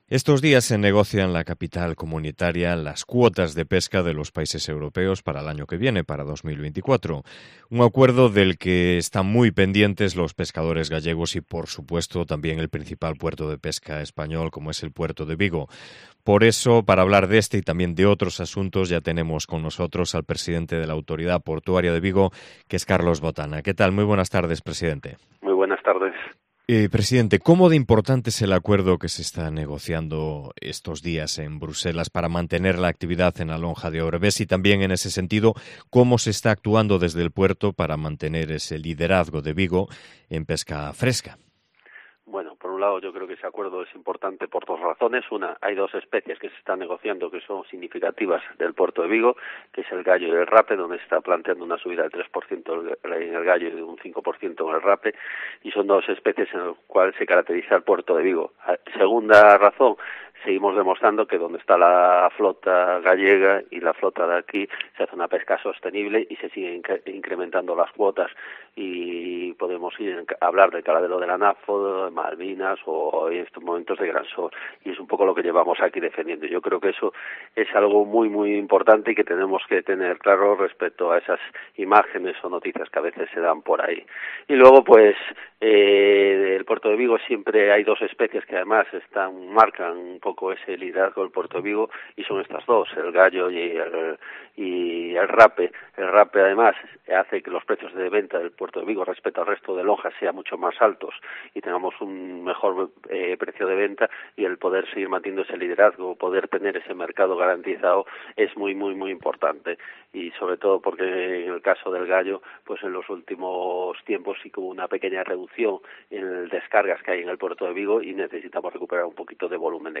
Entrevista con Carlos Botana, presidente de la Autoridad Portuaria de Vigo